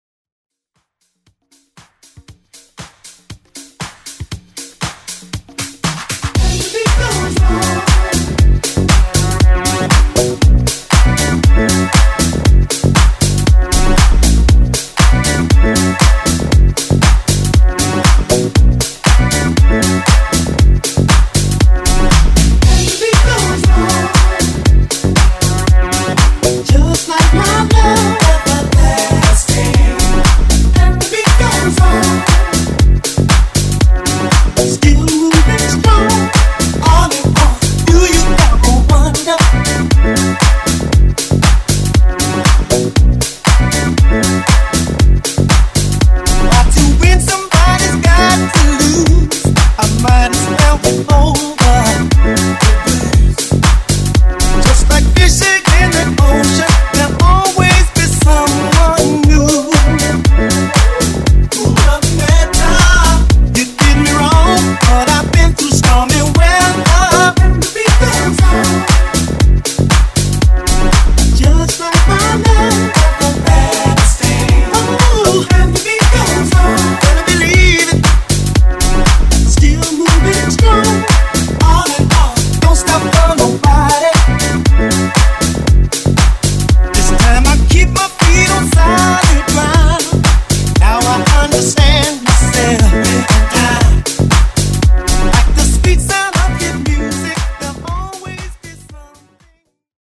Genres: FUTURE HOUSE , TOP40 Version: Clean BPM: 125 Time